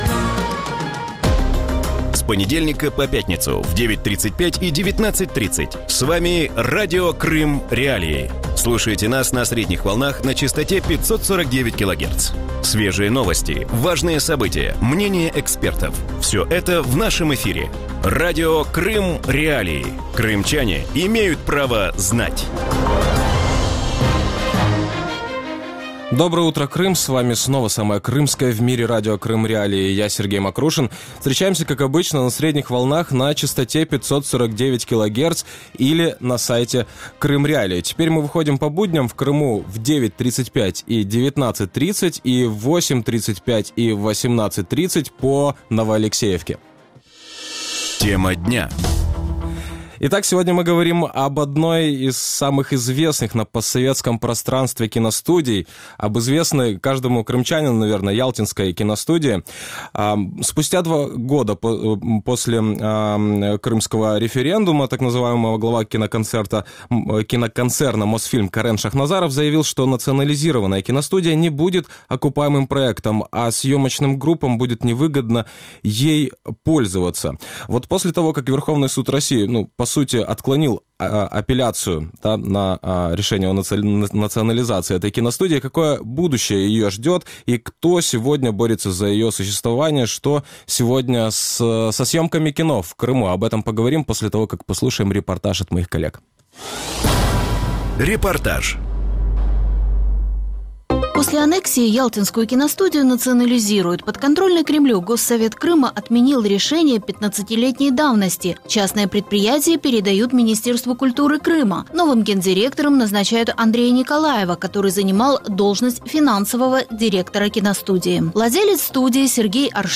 Вранці в ефірі Радіо Крим.Реалії говорять про одну з найвідоміших на пострадянському просторі кіностудію в Ялті.